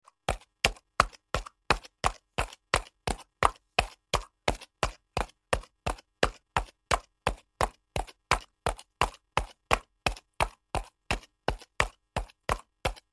دانلود صدای اسب 4 از ساعد نیوز با لینک مستقیم و کیفیت بالا
جلوه های صوتی